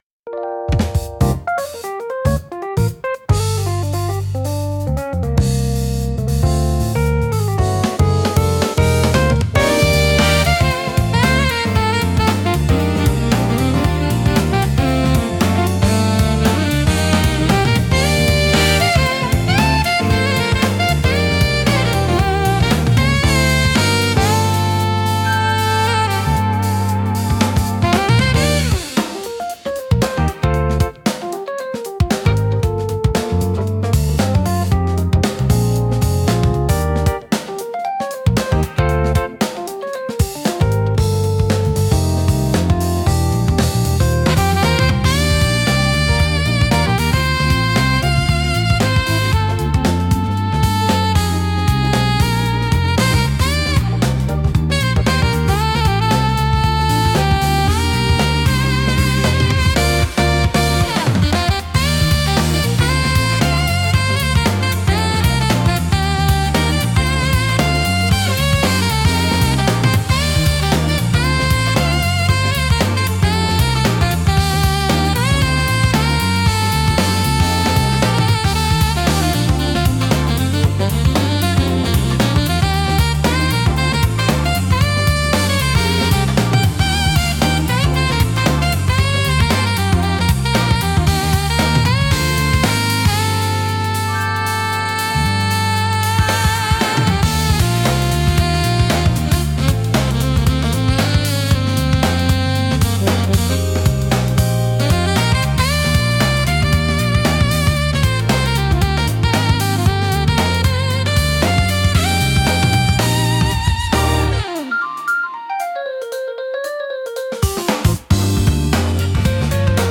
落ち着いた空気感を作り出しつつも、聴き疲れしにくい快適さがあり、ゆったりとした時間を楽しみたいシーンで多く活用されます。